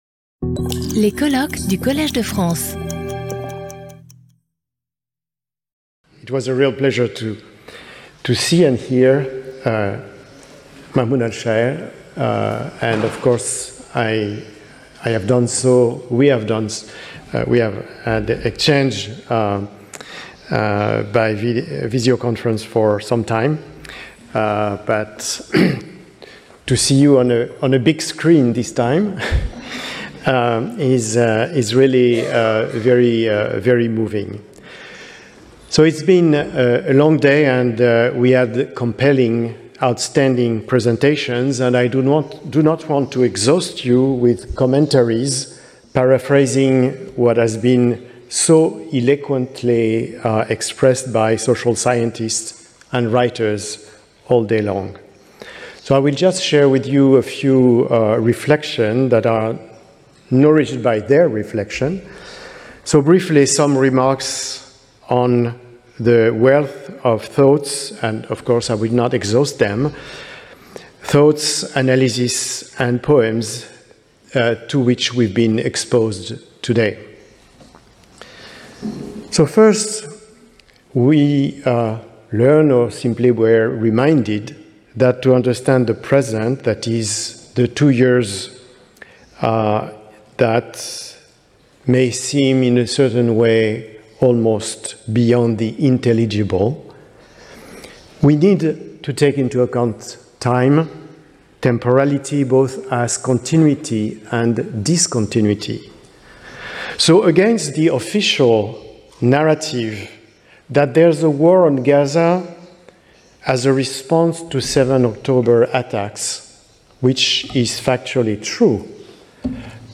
Lecture audio
Sauter le player vidéo Youtube Écouter l'audio Télécharger l'audio Lecture audio Cette vidéo est proposée dans une version doublée en français.